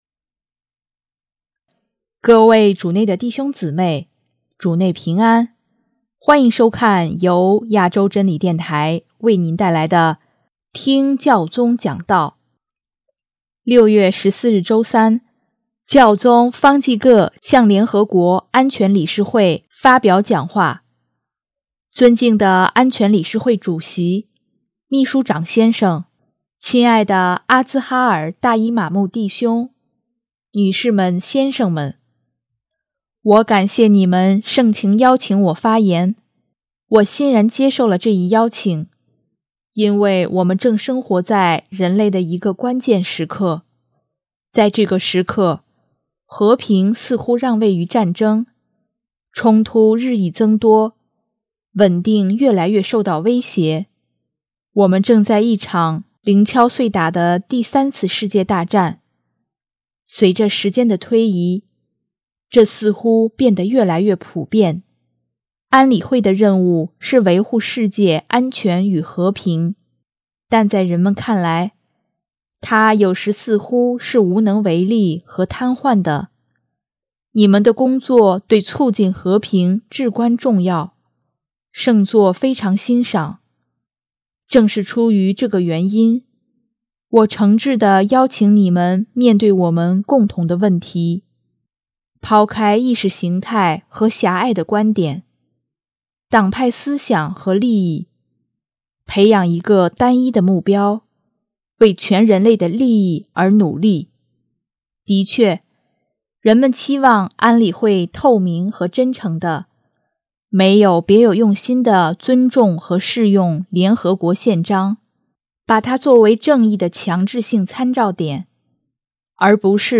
【听教宗讲道】|兄弟情谊不是一个抽象的概念，而是一个真正的出发点
6月14日周三，教宗方济各向联合国安全理事（THE SECURITY COUNCIL OF THE UNITED NATIONS）会发表讲话：